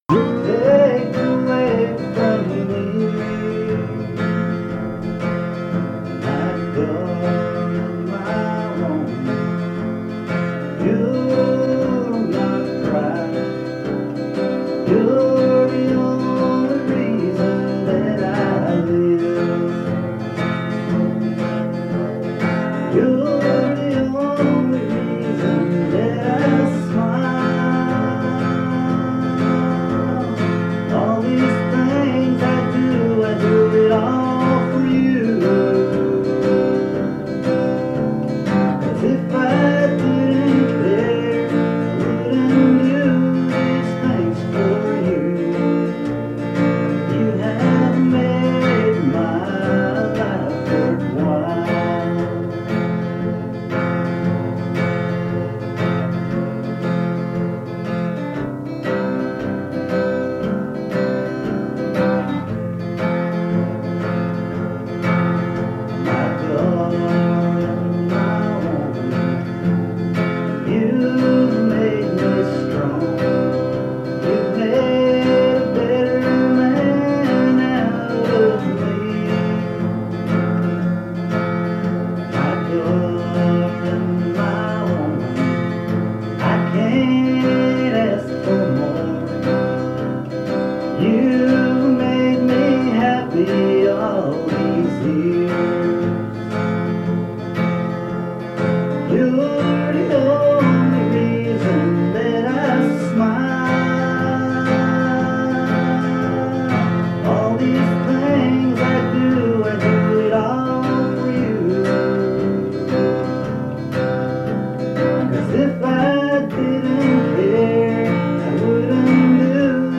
Fait partie de Musical performances